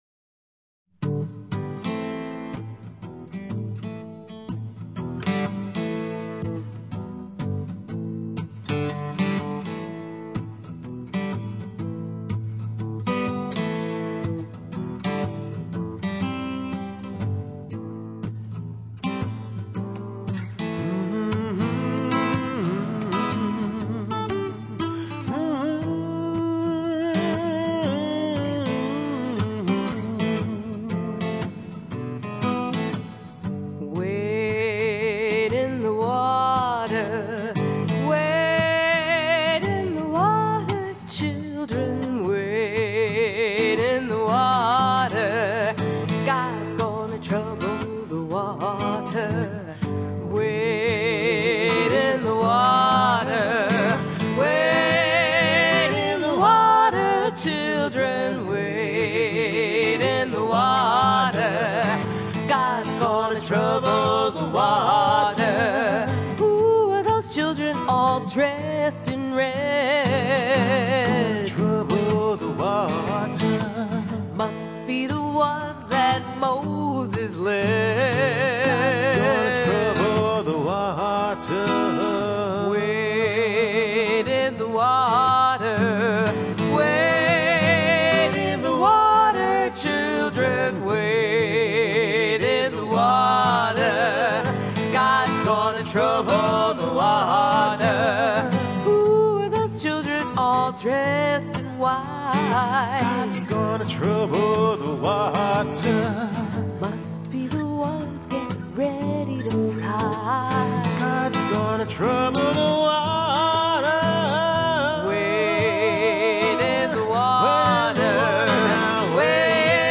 This is an absolute favorite of ours and one of the most requested songs in our repetoire. A spiritual of great power and hope that was also used to give practical reminders to fugitives ... travel near rivers and streams for cover, safety, food and direction.